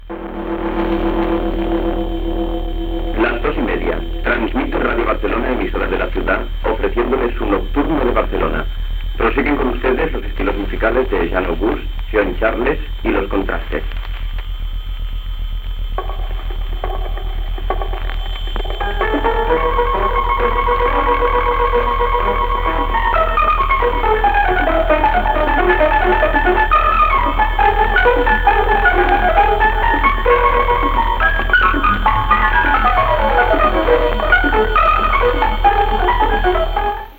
Presentació d'un tema musical.